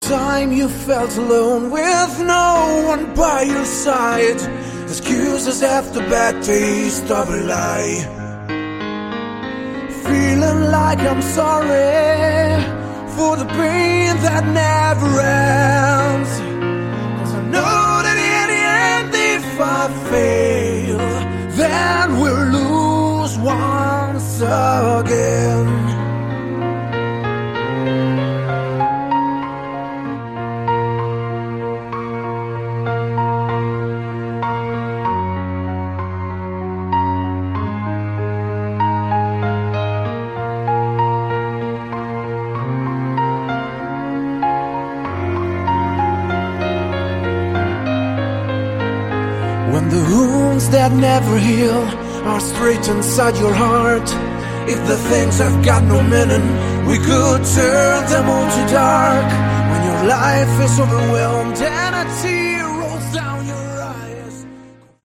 Category: Hard Rock
vocals
guitars
bass
keyboards
drums